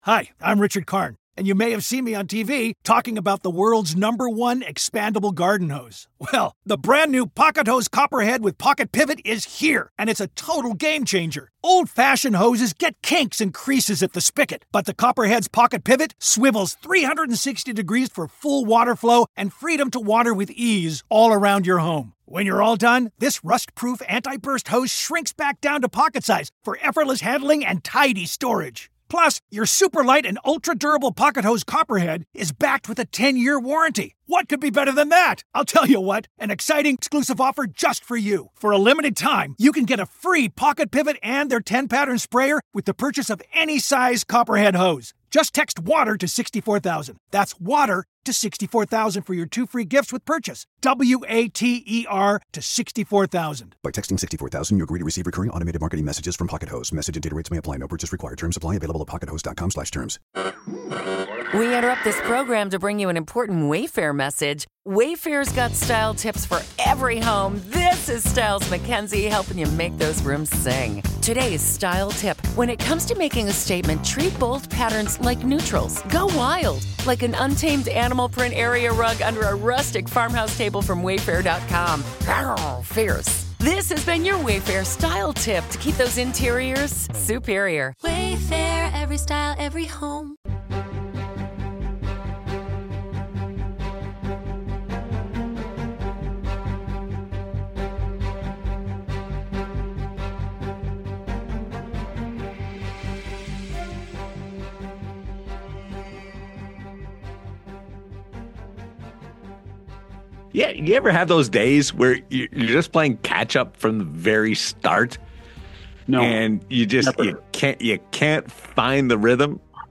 Marty Walsh, Executive Director of the NHLPA and former U.S. Secretary of Labor, joins 100% Hockey for a wide-ranging conversation. A lifelong hockey fan, Walsh shares his passion for the game before diving into the current state of CBA negotiations, including key topics like ...